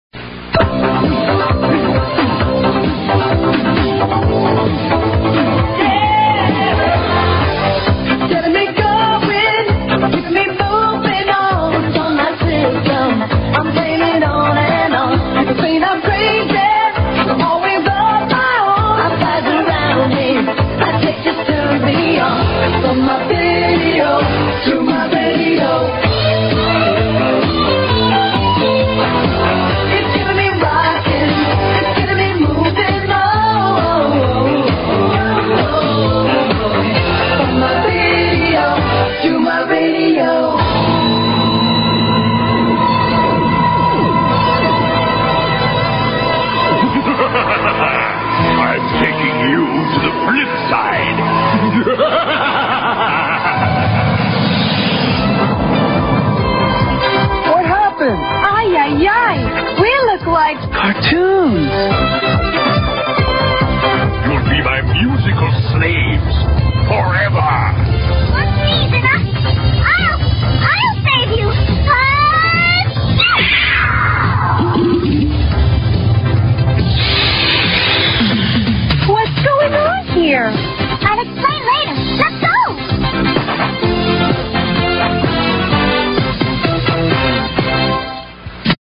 Tags: Cartoons The 80's Theme Songs 80's Toons